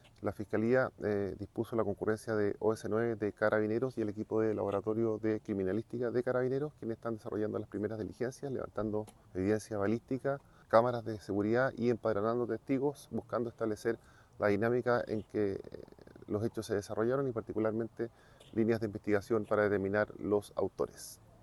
Los equipos “están desarrollando las primeras diligencias, levantando evidencia balística, cámaras de seguridad y empadronando testigos, buscando establecer la dinámica en que los hechos se desarrollaron y particularmente líneas de investigación para determinar a los autores”, indicó el persecutor.